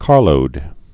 (kärlōd)